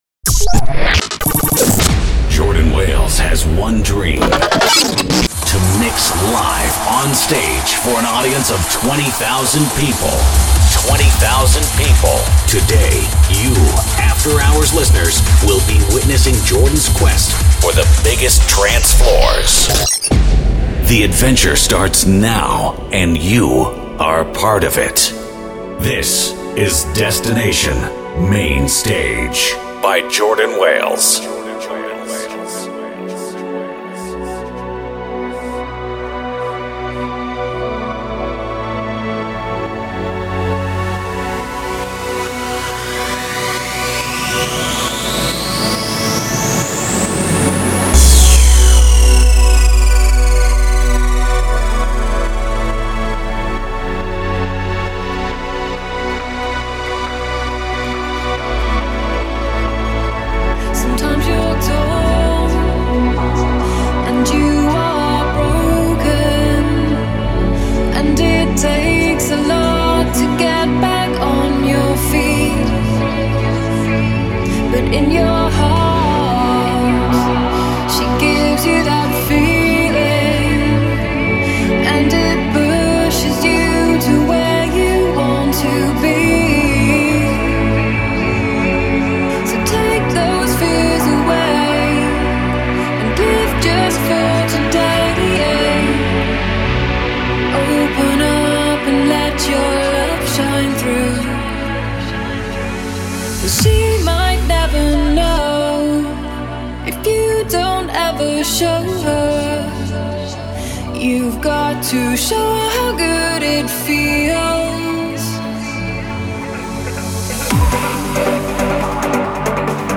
leading trance radio!